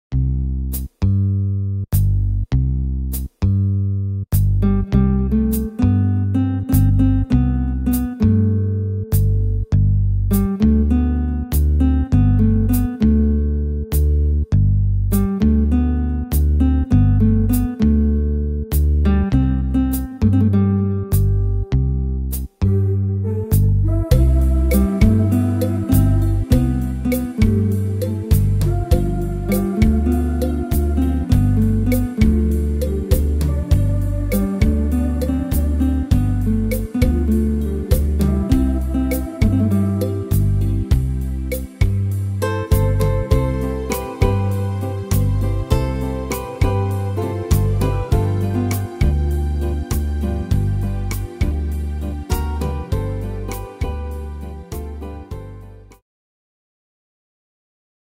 Tempo: 100 / Tonart: C-Dur